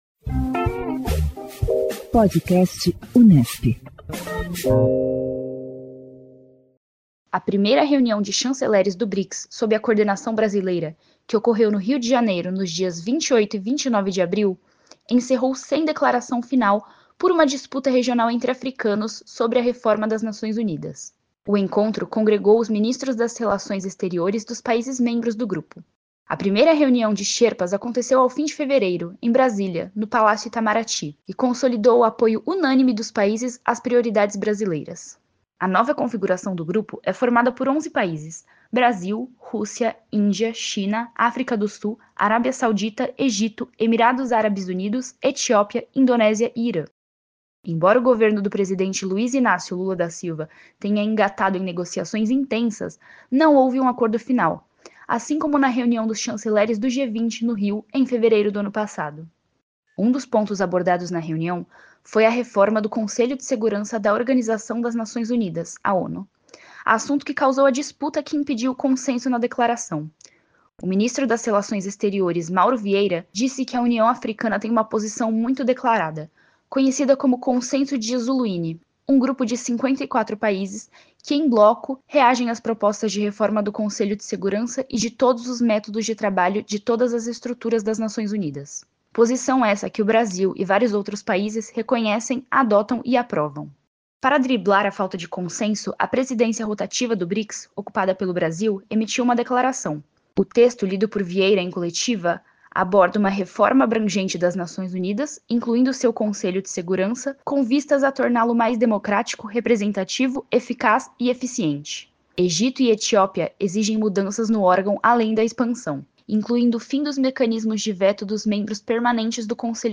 O Podcast Unesp divulga semanalmente entrevistas com cientistas políticos sobre as mais variadas pautas que englobam o universo político e as relações internacionais.